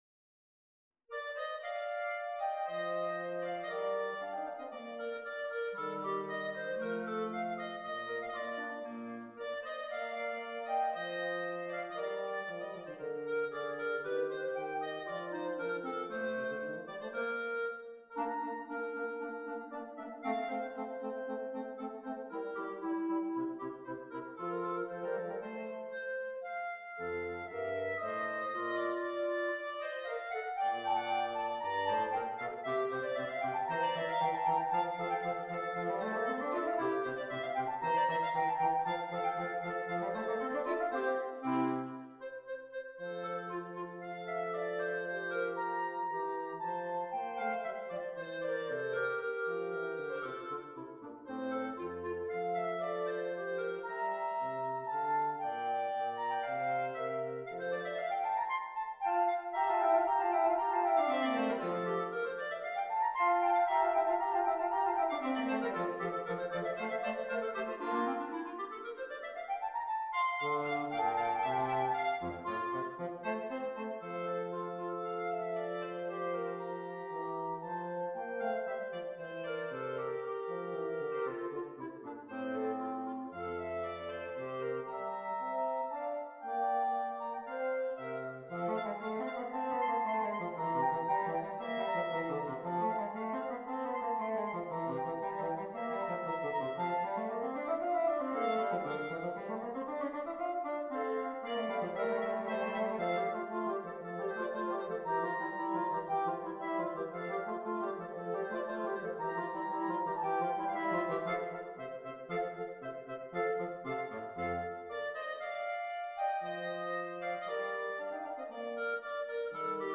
clarinetto basso